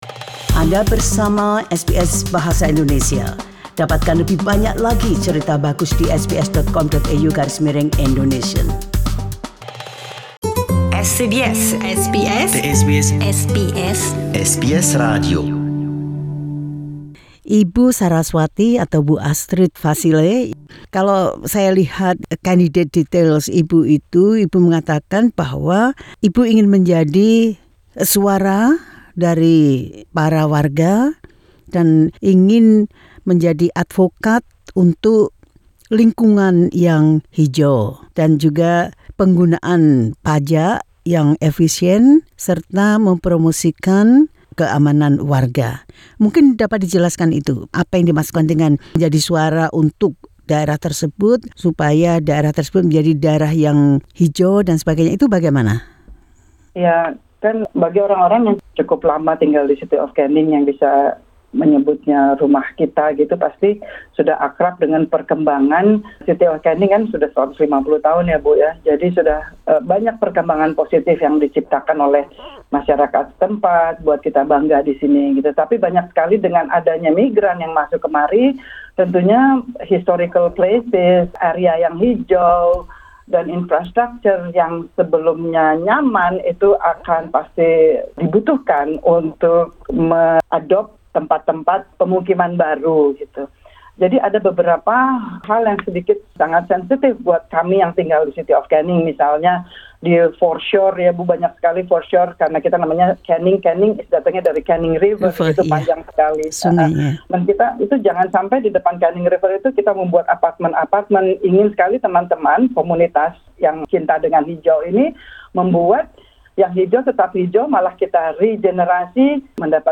Dalam wawancaranya